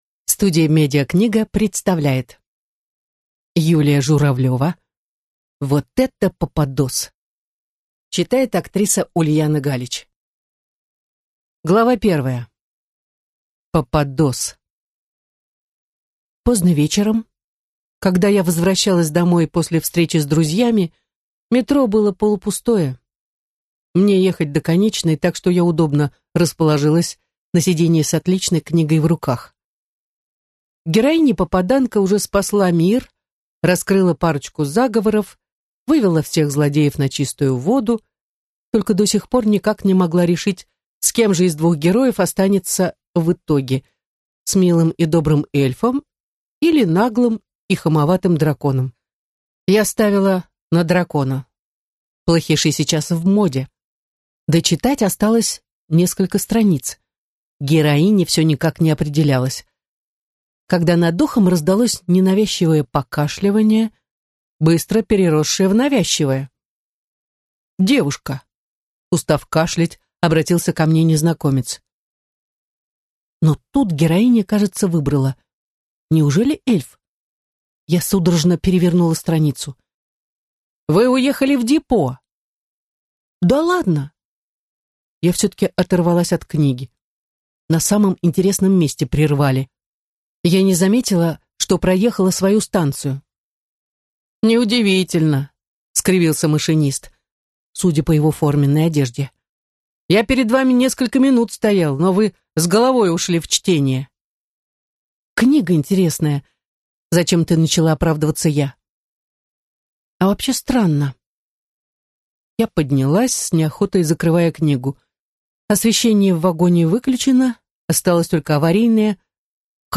Аудиокнига Вот это попадос!
Прослушать и бесплатно скачать фрагмент аудиокниги